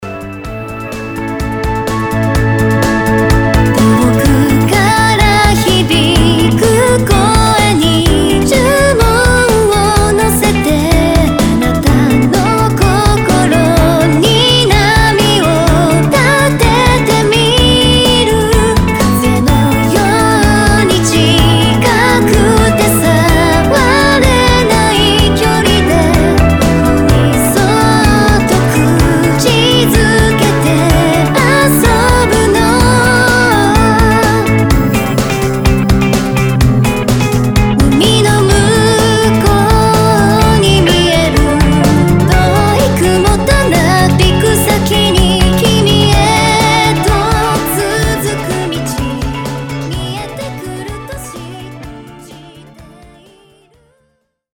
きまぐれオレンジPOP